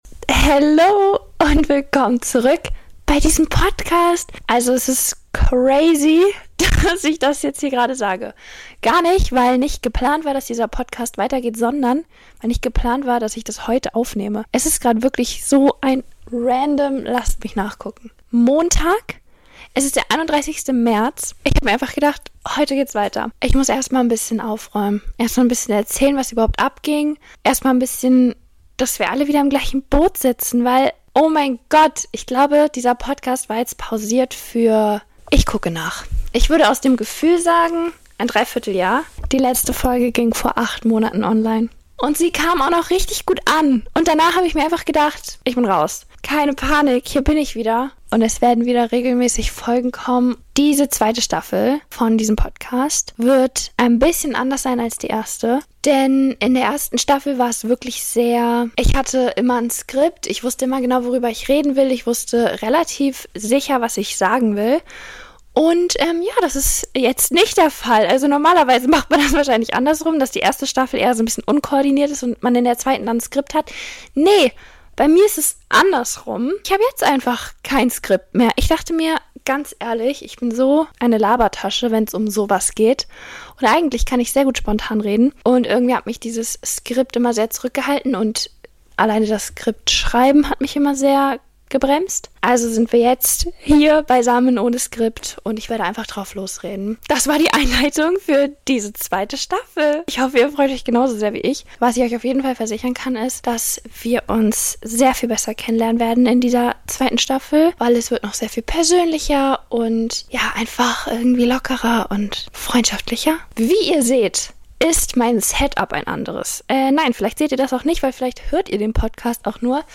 Beschreibung vor 1 Jahr ! kurz vorne weg: der Ton wird ab der nächsten Folge wieder gut sein :) 8 Monate Pause. 1000 Gedanken.
Kein Skript, nur echte Gedanken, auf die man meistens nachts kommt.